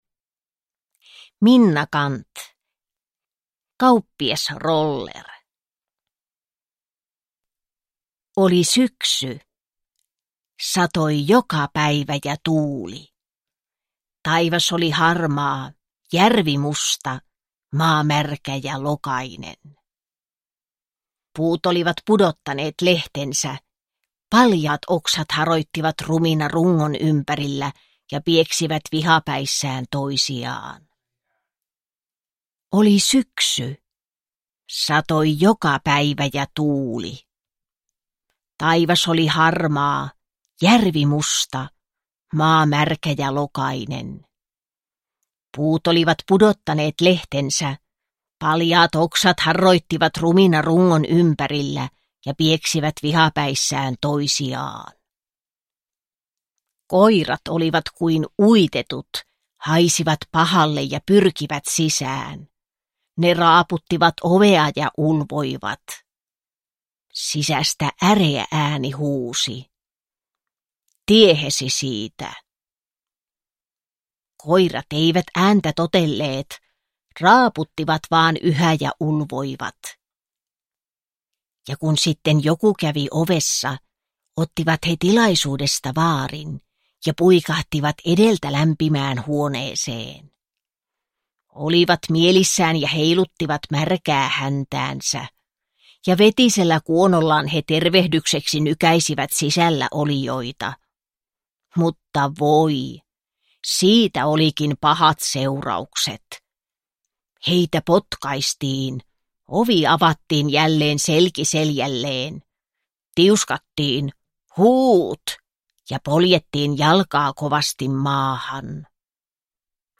Kauppias Roller / Ljudbok